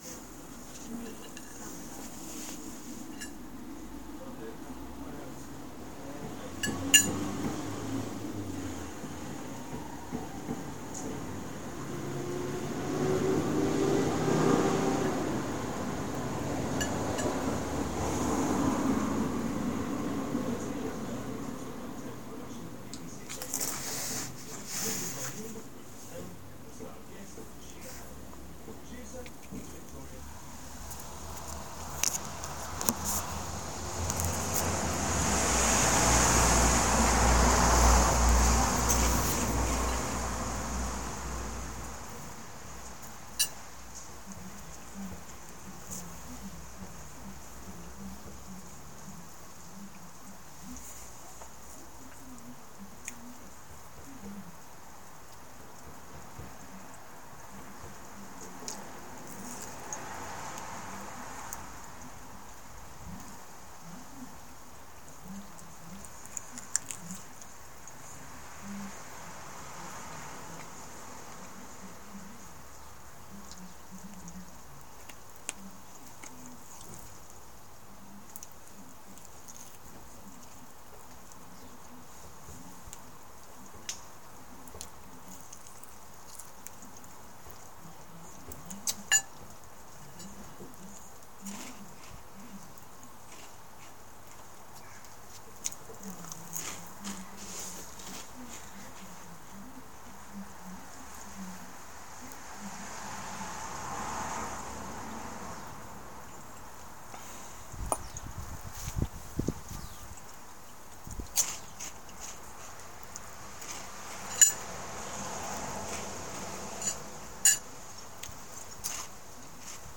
Lunch sitting on the step ambience